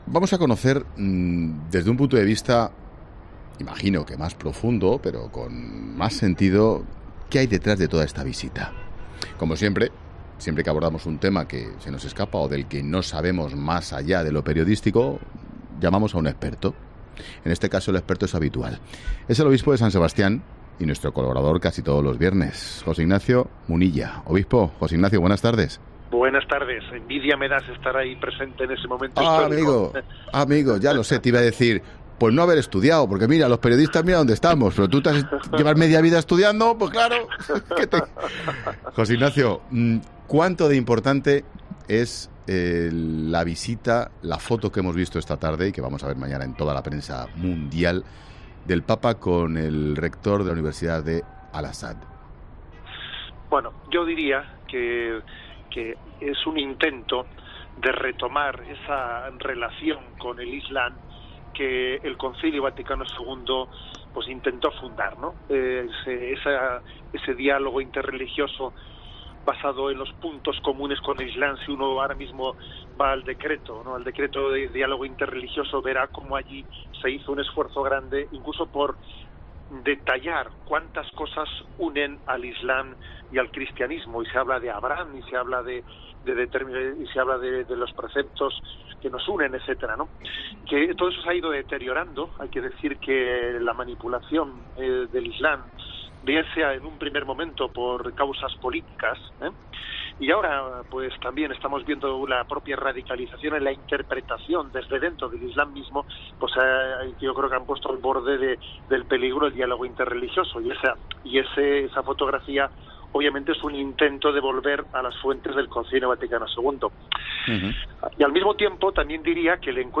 Este viernes se ha emitido 'La Tarde', con todo el equipo dirigido por Ángel Expósito, desde El Cairo (Egipto), ciudad en la que el Papa Francisco ha participado en una conferencia internacional para la paz, auspiciada por la Universidad suní Al-Azhar, donde se ha reunido con el gran Imám para pedir “un 'no' fuerte y claro” a toda violencia, venganza y odio cometidos en nombre de Dios.